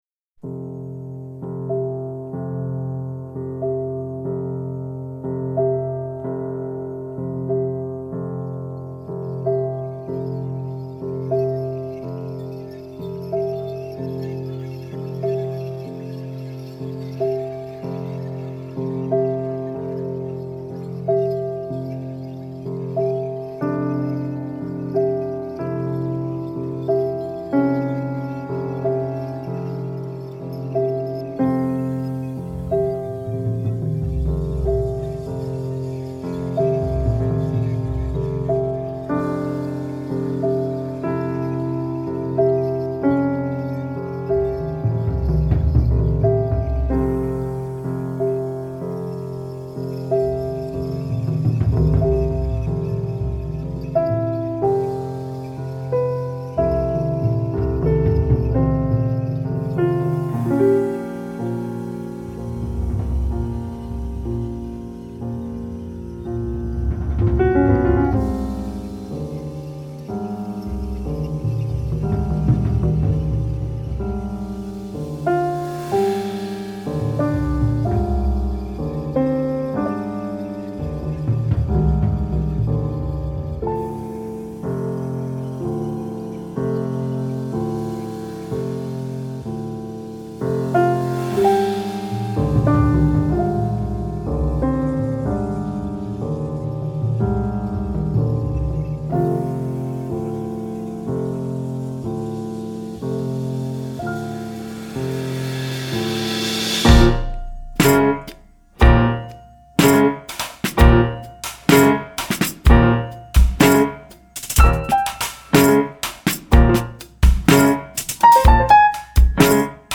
Piano and drums. No bass.